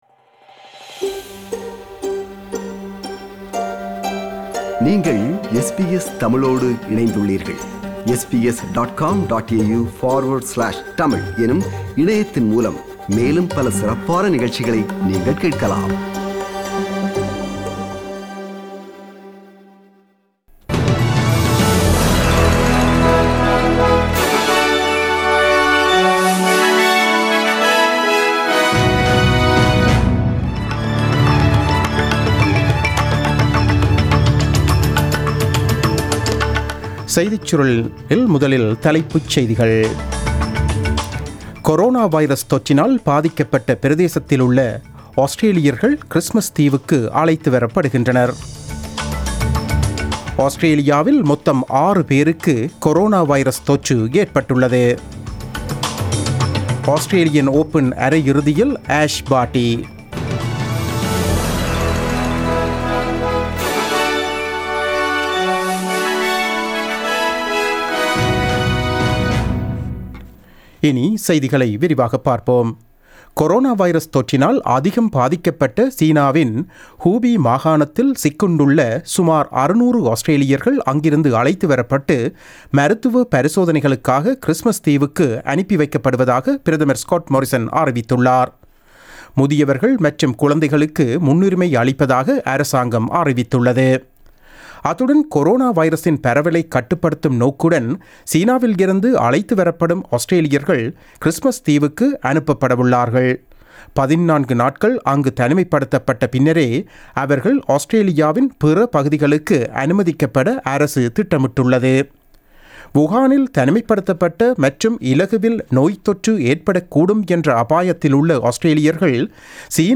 The news bulletin broadcasted on 29 January 2020 at 8pm.